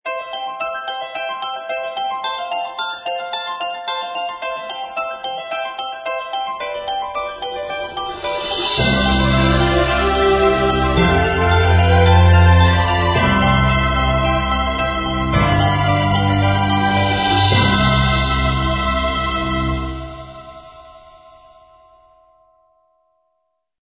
明亮简洁片头音乐